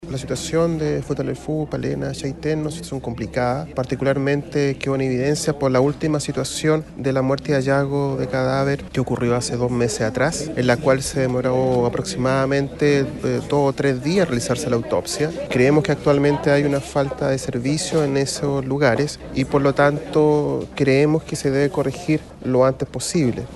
El Fiscal Regional Subrogante, Jorge Raddatz, afirmó que se debe corregir la falta del Servicio Medico Legal en dicha zona.